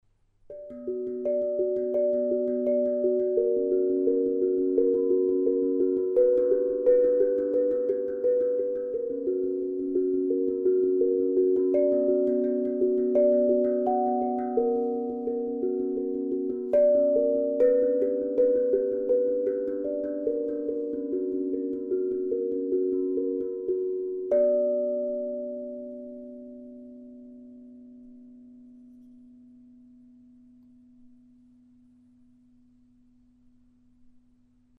Die Zenko breitet ihre Klänge in weichen und sphärischen Wellen aus.
Kleine Improvisation
Solstice impro.mp3